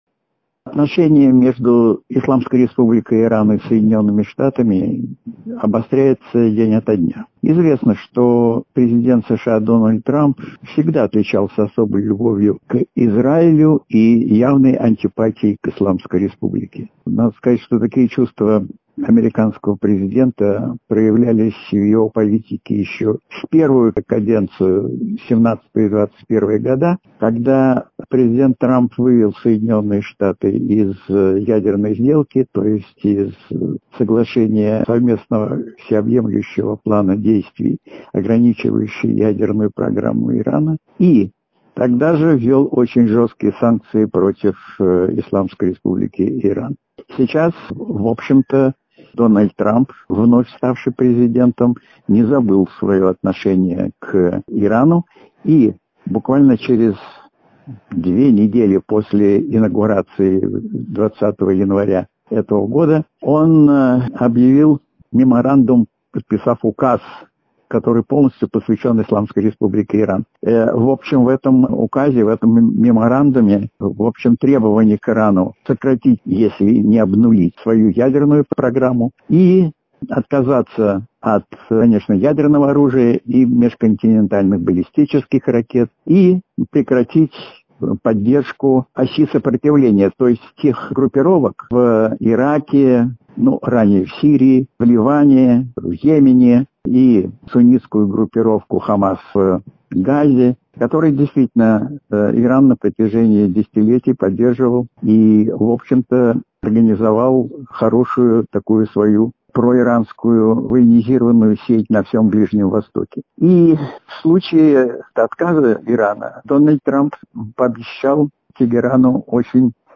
аудиоверсия программы